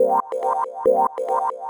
K-1 Reverse Stab.wav